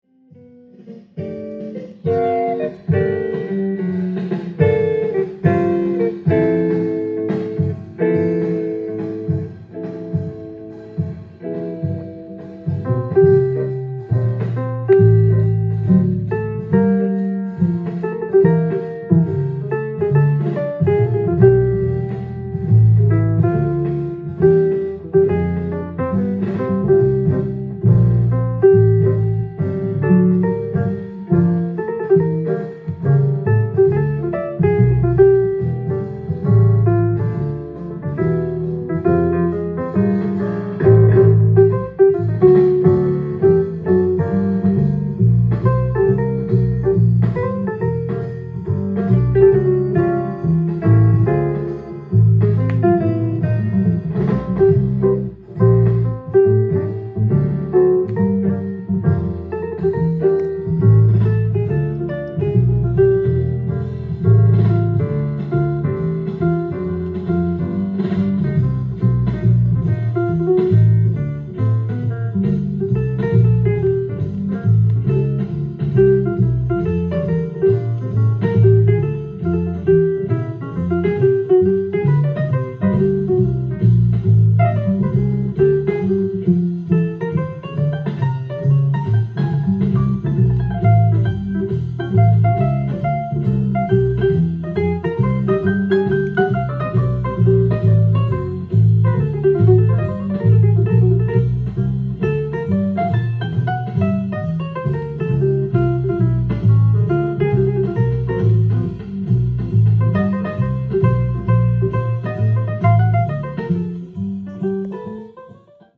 ジャンル：JAZZ-ALL
店頭で録音した音源の為、多少の外部音や音質の悪さはございますが、サンプルとしてご視聴ください。
Quartet